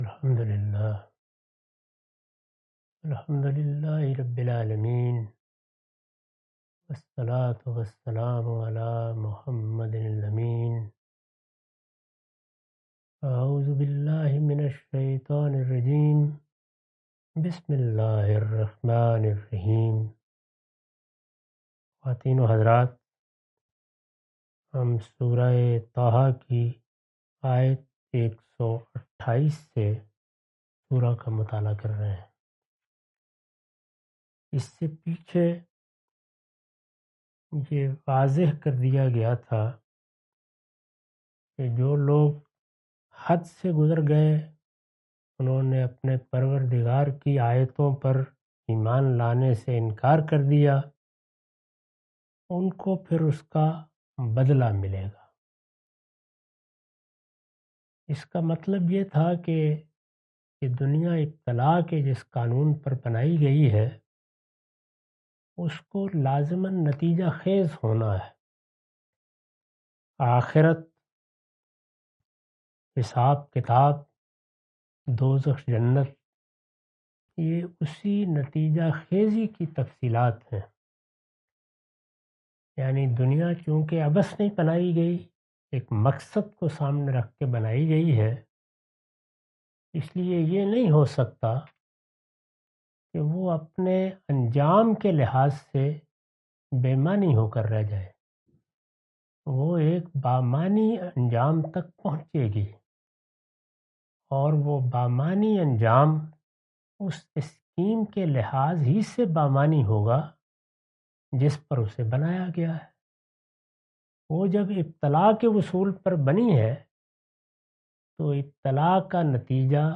Surah Taha A lecture of Tafseer-ul-Quran – Al-Bayan by Javed Ahmad Ghamidi. Commentary and explanation of verses 128-130.